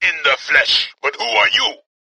―Reelo to Kyle Katarn — (audio)
Reelo first appeared as one of the more major villains in Star Wars: Jedi Knight II: Jedi Outcast, where his voice was portrayed by Kevin Michael Richardson.
Reelo_in_the_flesh.ogg